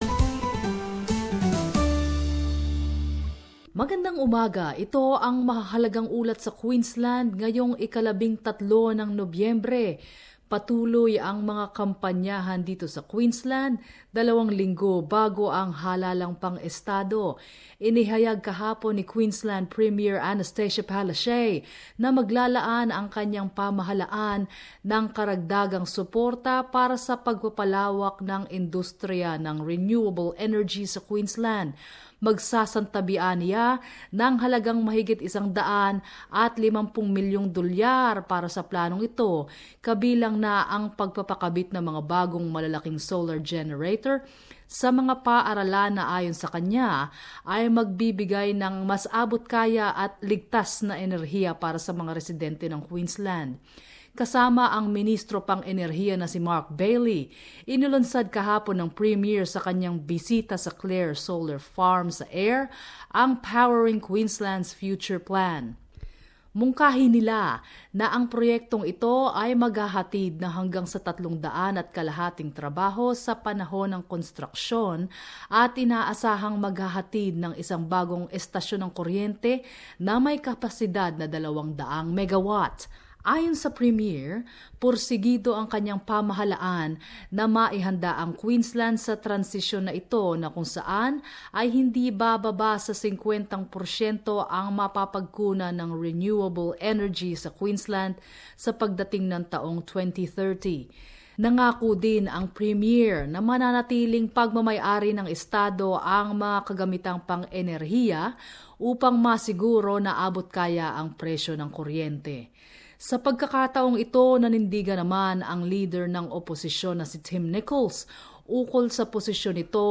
News from Queensland